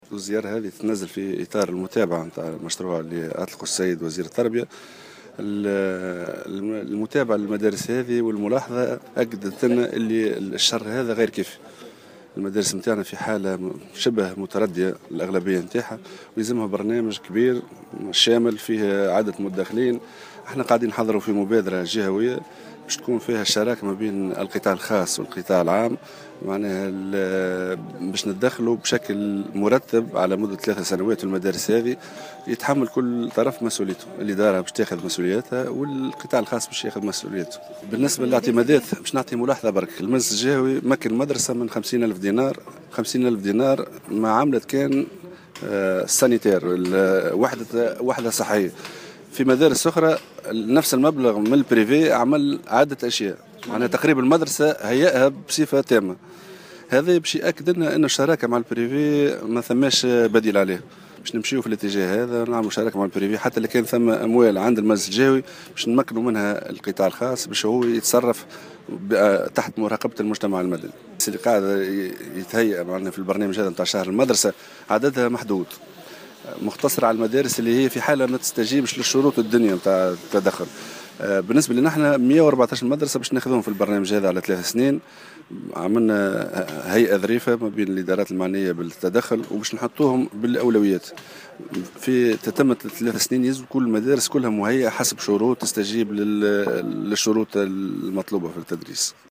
أكد والي نابل،سمير رويهم اليوم الجمعة على هامش زيارة ميدانية لبعض المؤسسات التربوية في إطار شهر المدرسة على دور القطاع الخاص في معاضدة مجهود الدولة في صيانة المدارس وتهيئتها.